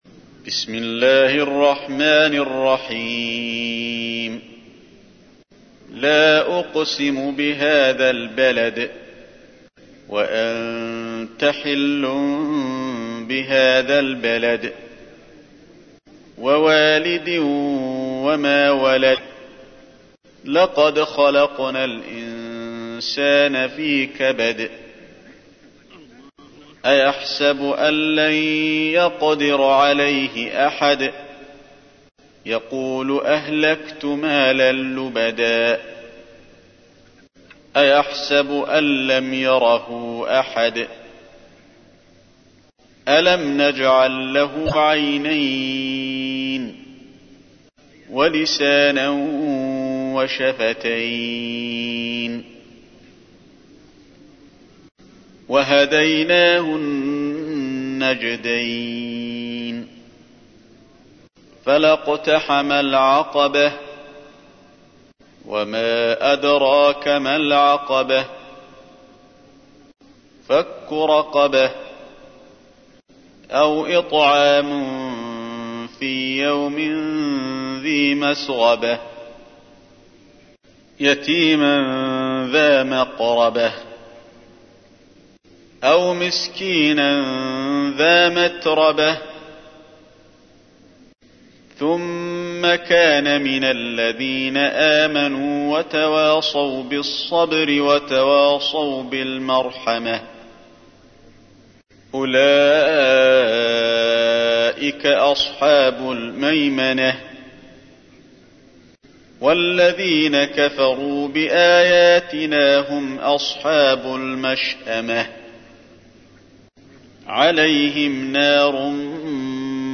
تحميل : 90. سورة البلد / القارئ علي الحذيفي / القرآن الكريم / موقع يا حسين